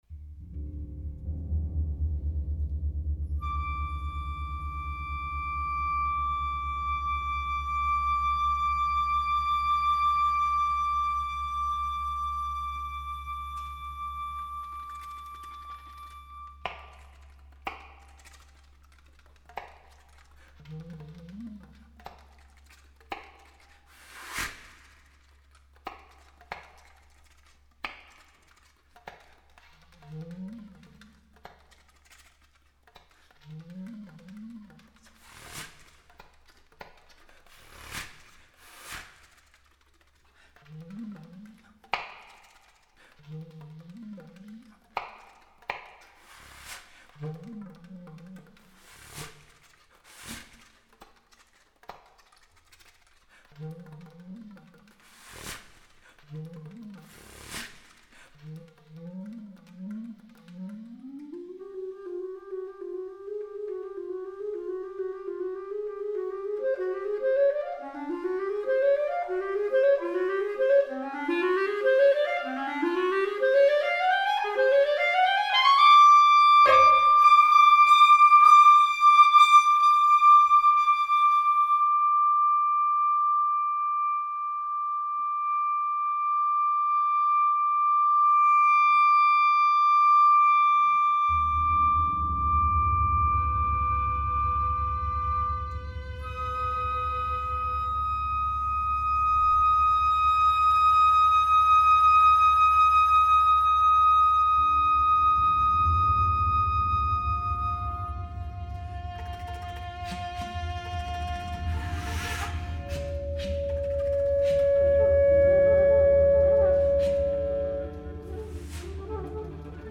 Reversible para flauta, clarinete, viola y piano